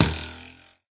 bowhit3.ogg